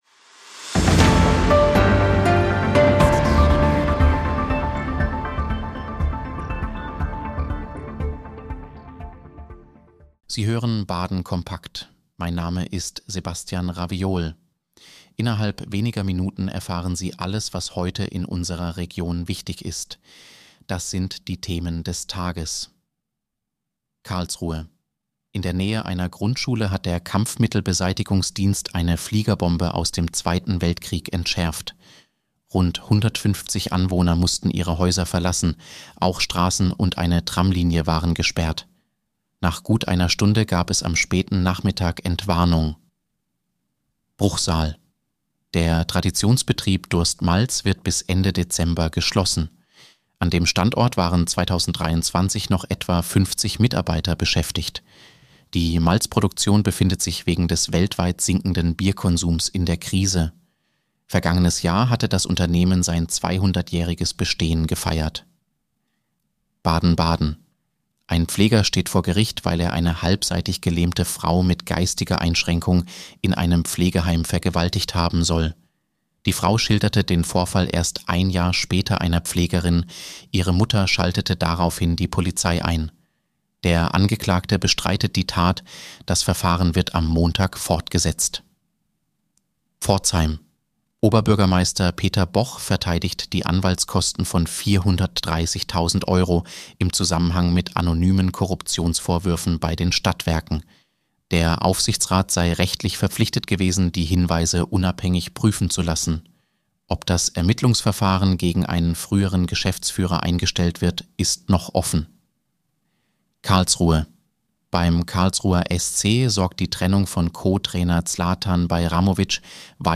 Nachrichtenüberblick Mittwoch, 10. Dezember 2025